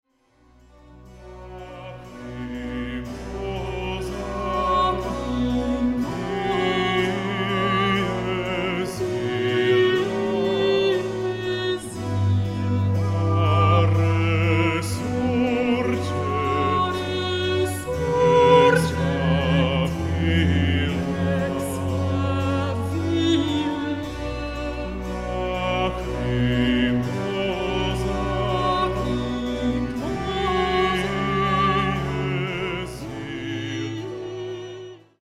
a haunting oratorio
scored for soloists, SATB choir and chamber orchestra